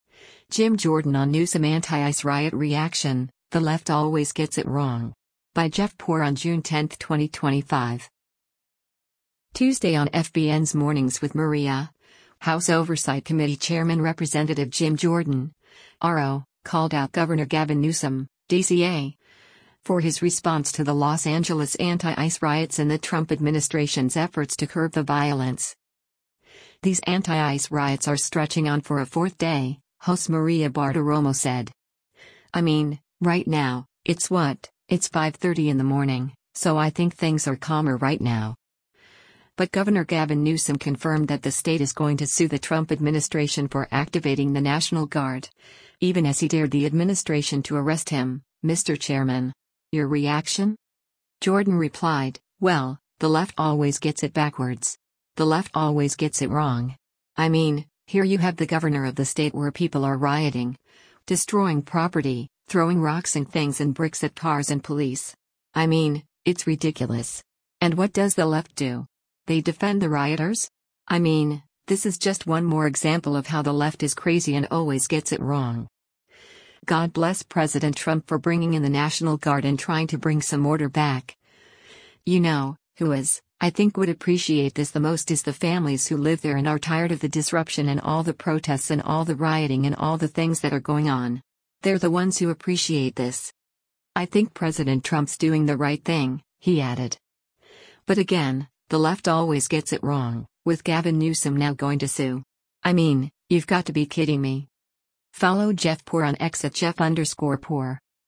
Tuesday on FBN’s “Mornings with Maria,” House Oversight Committee chairman Rep. Jim Jordan (R-OH) called out Gov. Gavin Newsom (D-CA) for his response to the Los Angeles anti-ICE riots and the Trump administration’s efforts to curb the violence.